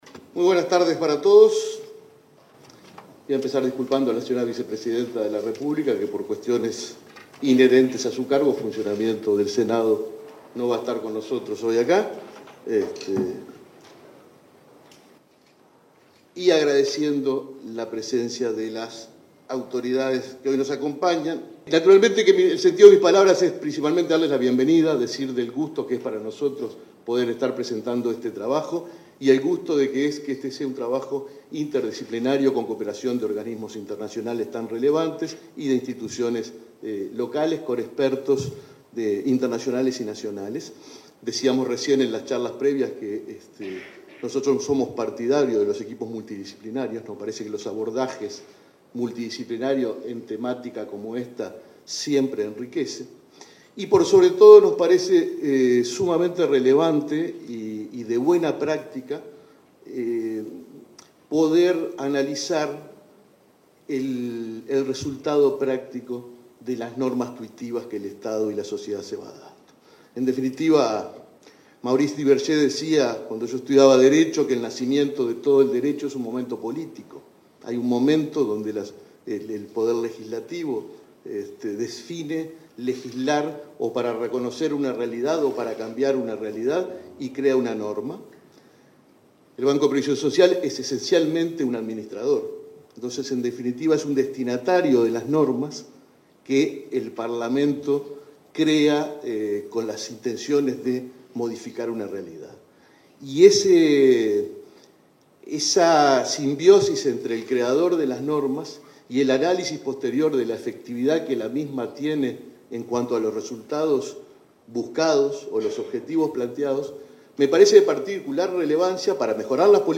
Conferencia de prensa por presentación de estudio sobre subsidio de medio horario para cuidados de recién nacidos
Conferencia de prensa por presentación de estudio sobre subsidio de medio horario para cuidados de recién nacidos 15/11/2022 Compartir Facebook X Copiar enlace WhatsApp LinkedIn Este 15 de noviembre, el Banco de Previsión Social (BPS) presentó un estudio sobre el uso del subsidio de medio horario para cuidados de recién nacidos. Participaron el presidente del BPS, Alfredo Cabrera, y la directora de la institución, Daniela Barindelli.